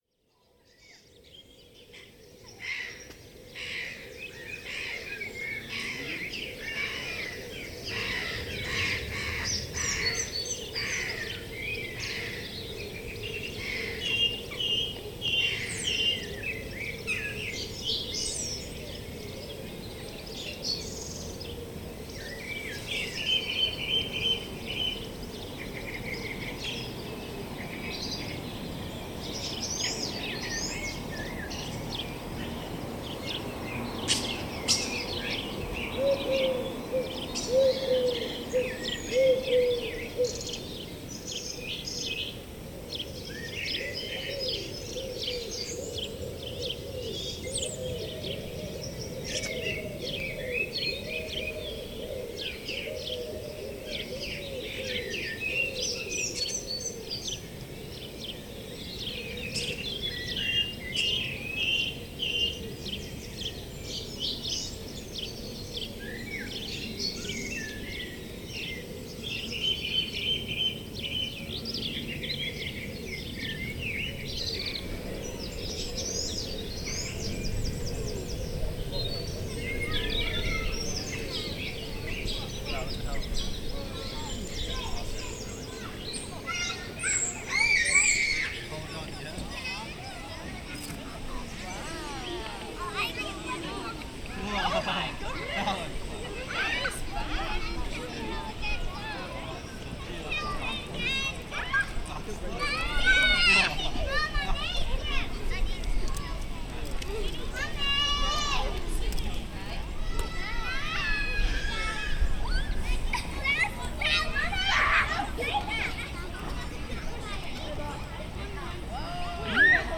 Enjoy listening to Glossop and its surrounding towns closer than you’ve ever done before!
From the dawn chorus in Hadfield, to the owls tooting in Simmondley.
LT-SOUNDSCAPES-AS-IT-IS-mixdown.mp3